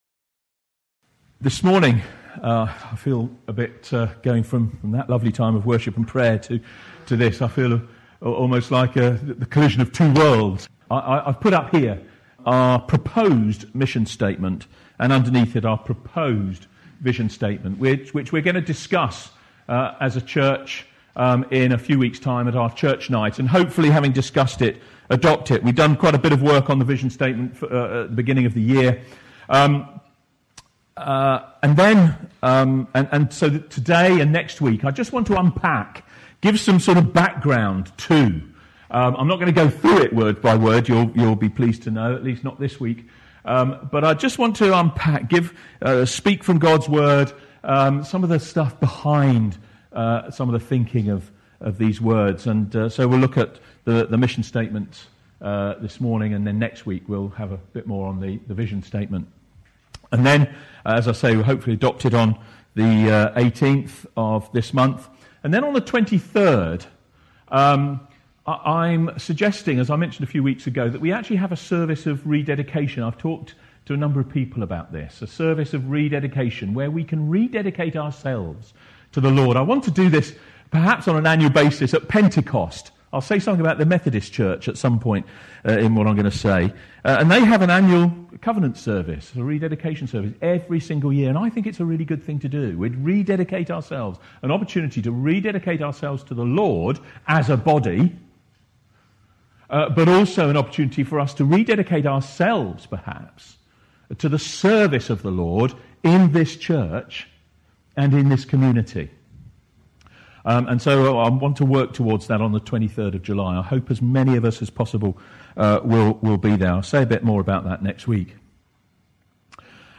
After a break in this sermon series, firstly during the refurbishment, and then for Easter and Pentecost, we returned to our series on our mission and vision.